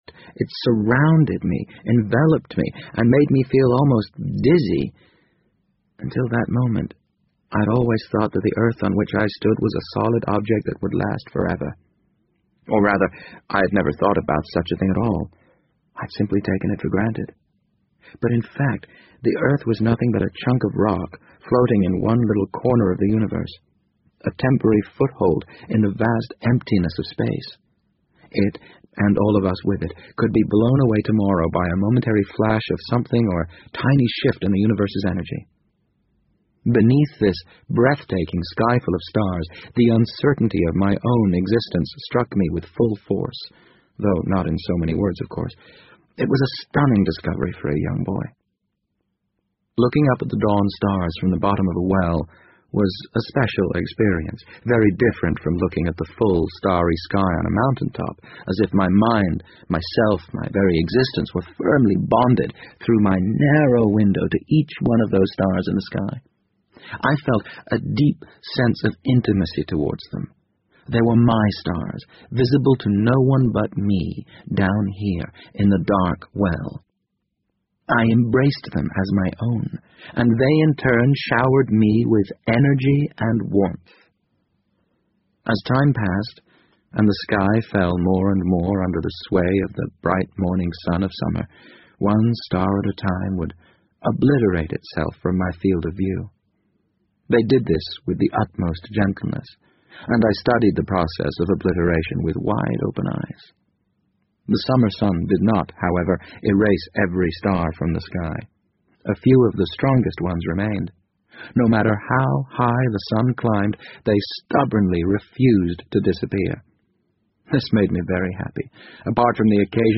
BBC英文广播剧在线听 The Wind Up Bird 007 - 2 听力文件下载—在线英语听力室